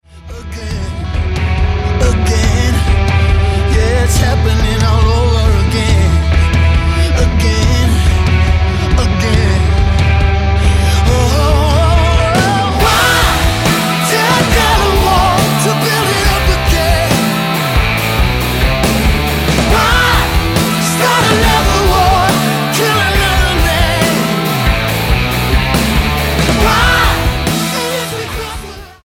STYLE: Blues